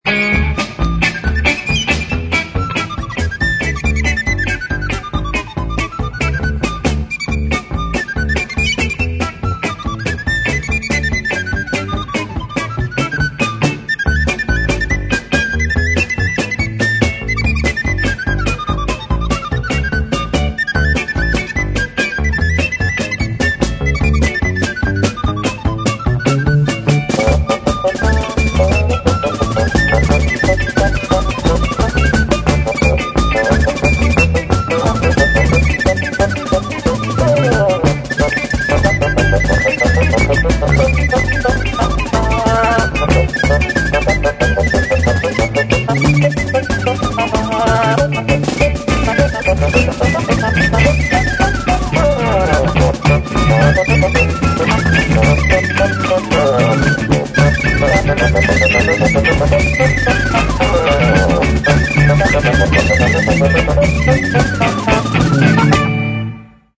Stereo, 1:06, 56 Khz, (file size: 457 Kb).